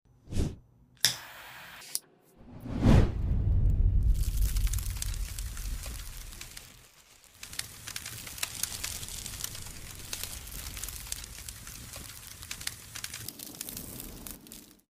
Burning of a blue feather sound effects free download
Burning of a blue feather Mk ASMR macro close-up